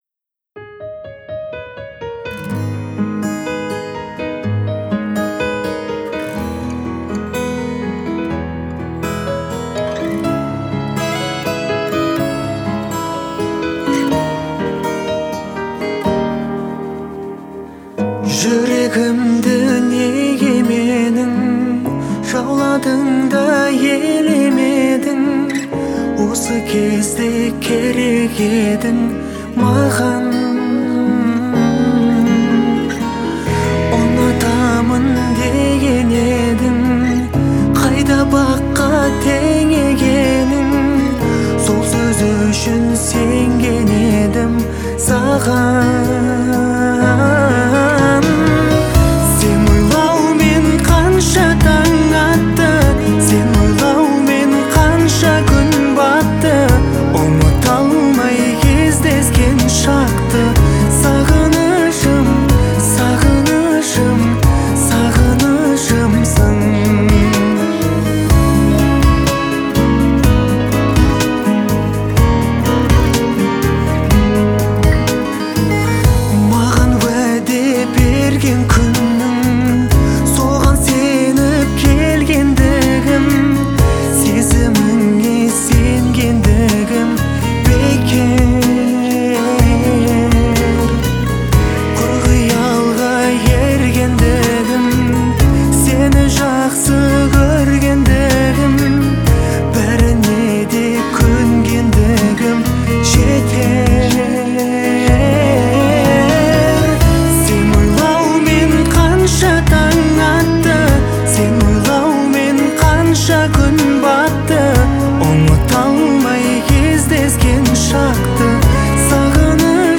это трогательная песня в жанре казахского поп-фолка